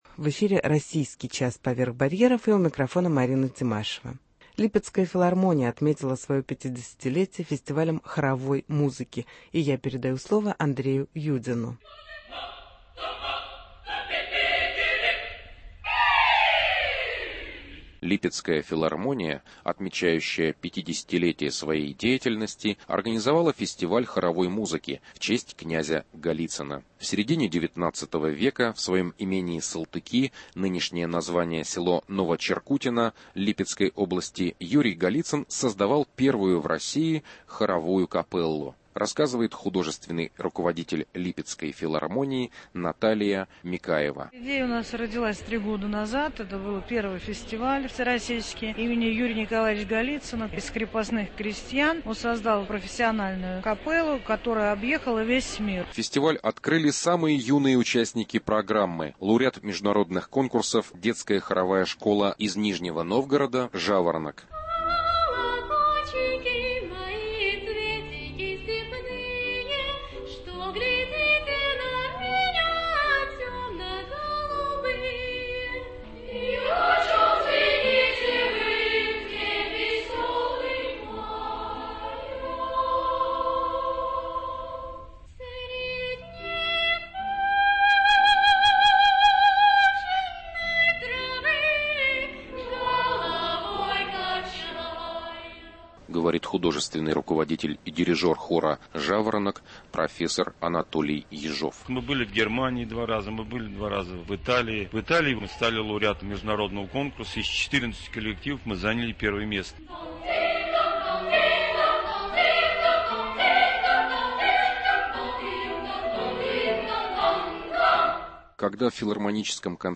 фестиваль хоровой музыки в Липецке